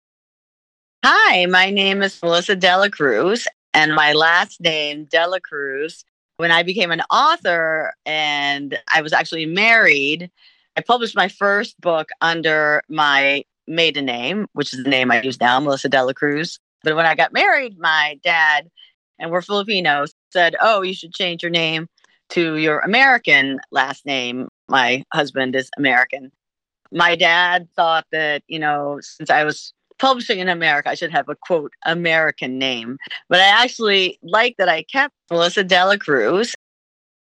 Audio Name Pronunciation
A recording introducing and pronouncing Melissa de la Cruz.